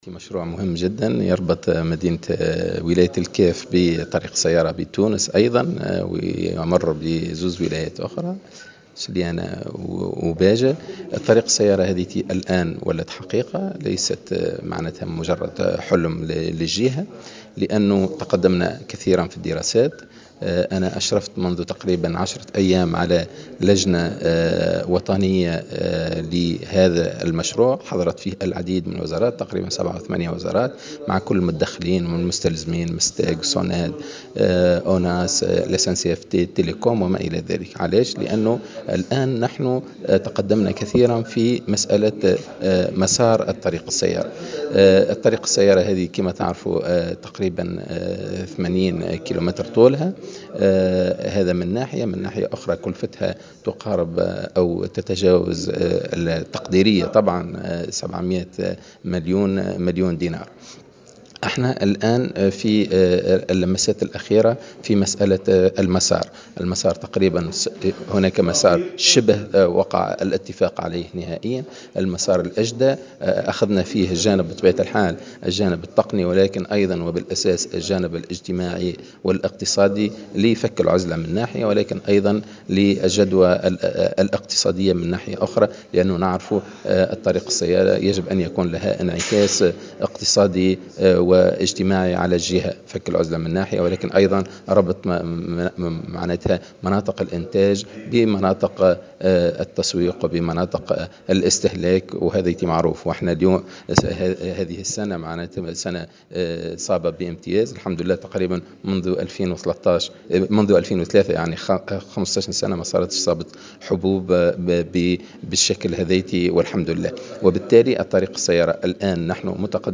وأضاف في تصريح لمراسل "الجوهرة أف أم" على هامش زيارة أداها إلى الكاف، أن هذه الطريق ستربط ولاية الكاف بالطريق السيارة بتونس مرورا بسليانة وباجة، مشيرا إلى أن الطريق ستكون على طول 80 كلم وبكلفة تقديرية تتجاوز 700 مليون دينار.